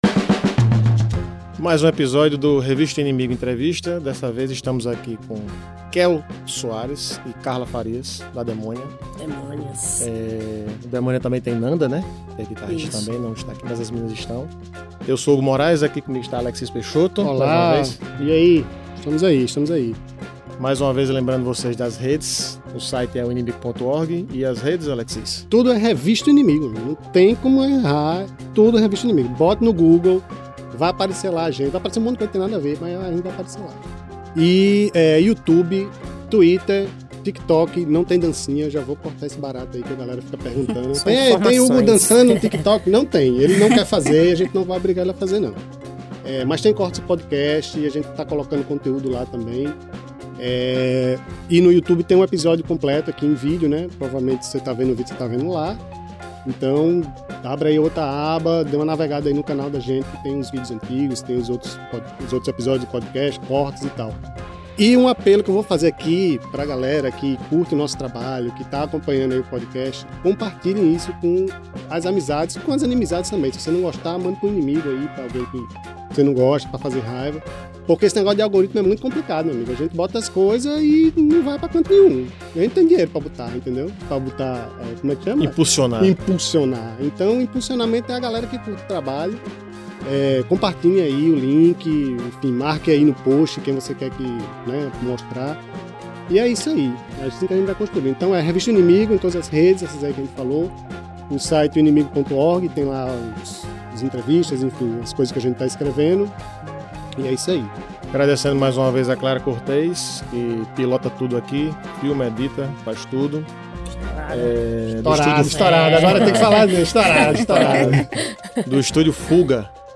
Gravado e editado no Estúdio Fuga, Natal/RN.
revista-o-inimigo-entrevista-demonia.mp3